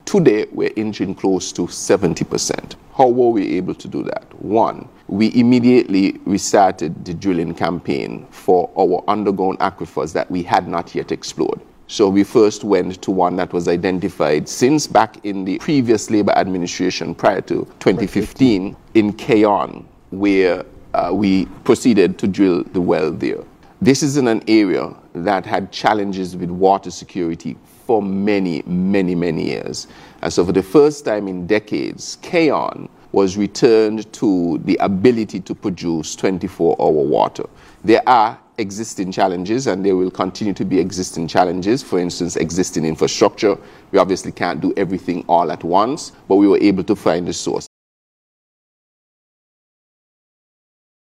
Minister Maynard gives us more.
Minister responsible for Water Services-St. Kitts, the Hon. Konris Maynard.